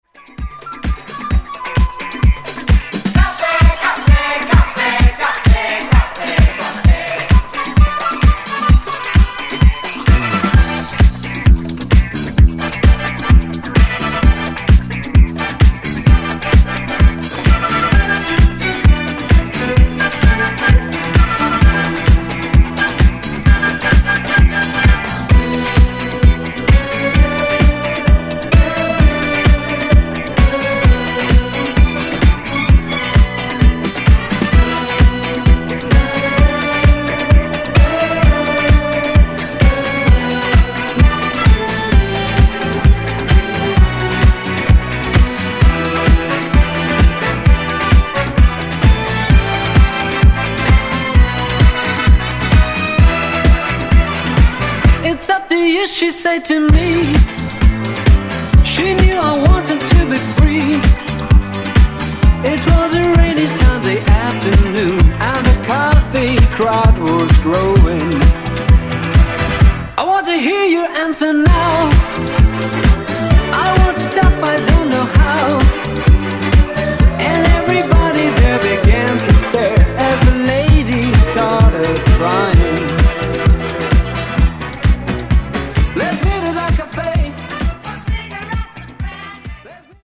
Genres: 70's , RE-DRUM
Clean BPM: 129 Time